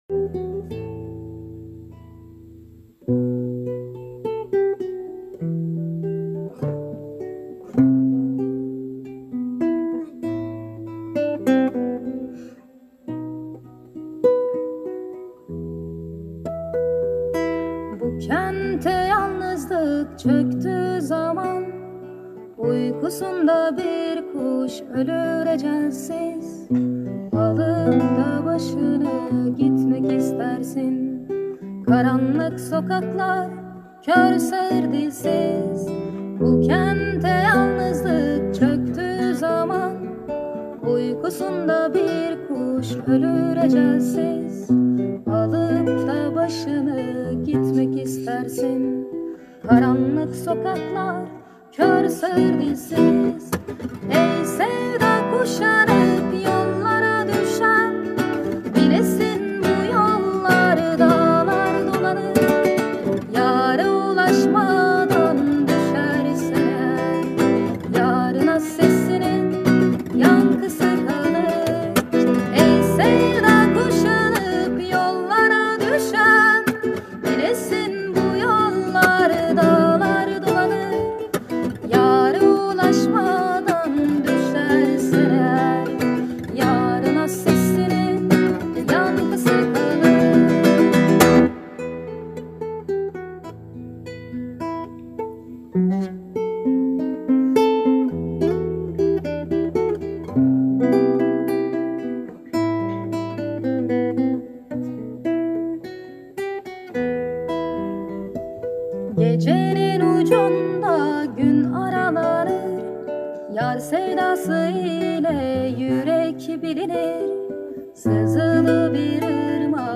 Жанр: Турецкая музыка